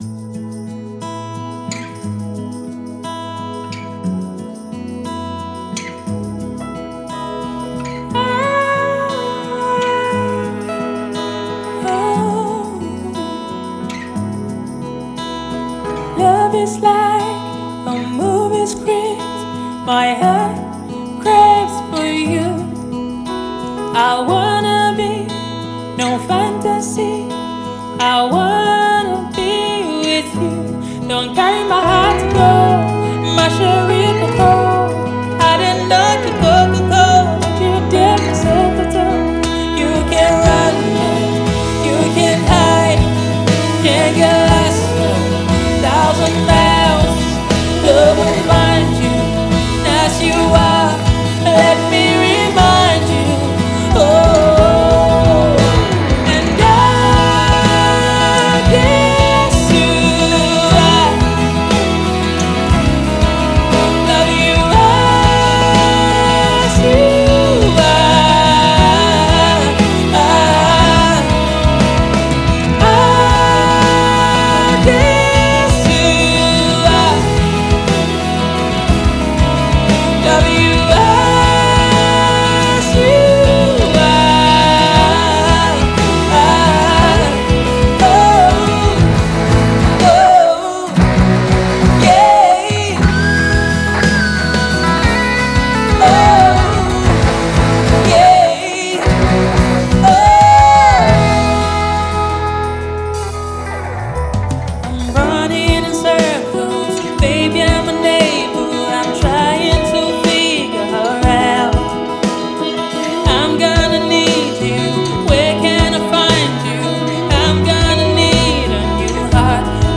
independent African rock